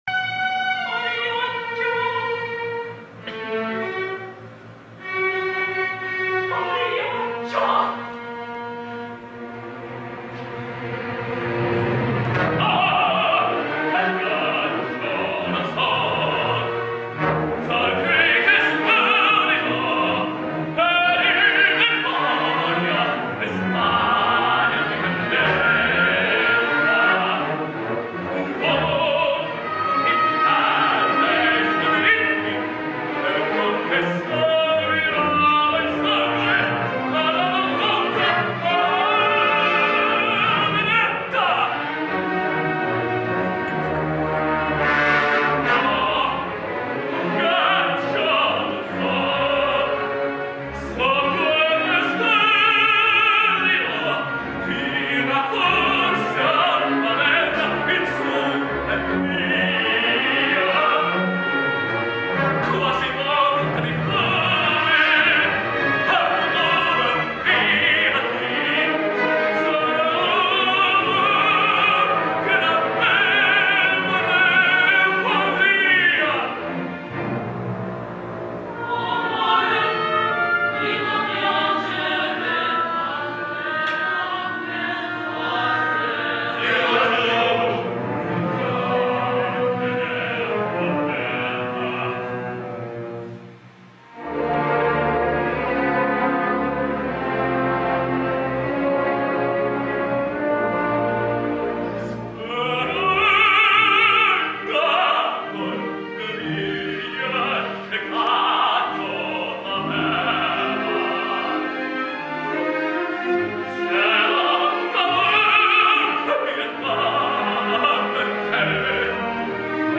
Opernsänger, Tenor